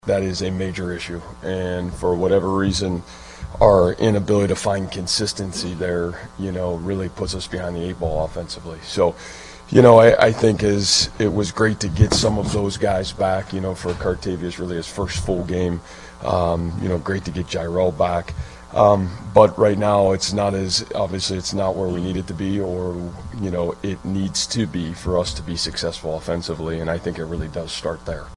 Iowa State coach Matt Campbell.